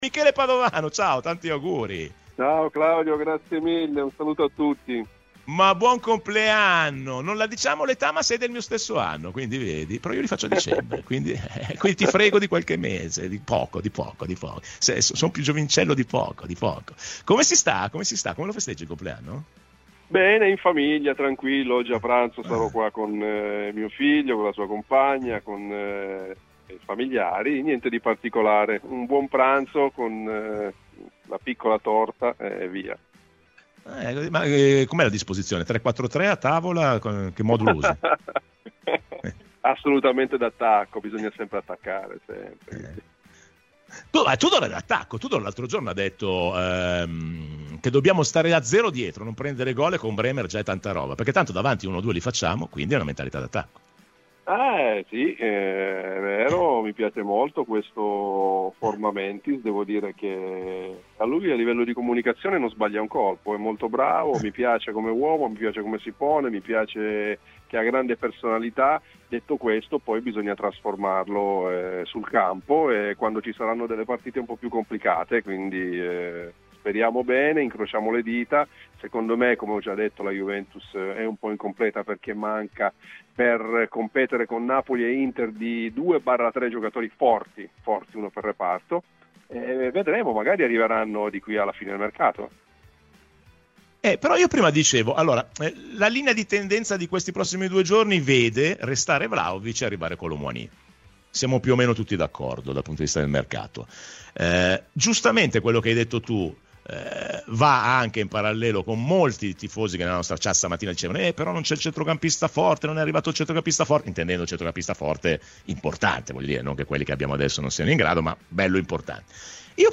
Michele Padovano, ex giocatore della Juventus, è intervenuto ai microfoni di Radio Bianconera nel corso della trasmissione Rassegna Stramba.